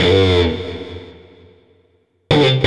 Drone FX 02.wav